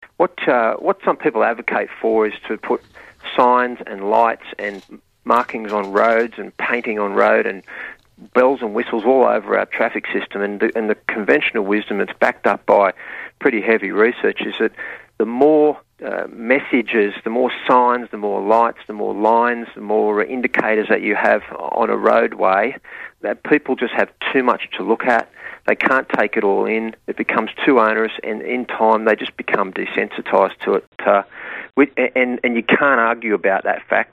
School Zone Dragon's Teeth Ineffective According to Roads Minister - Radio interview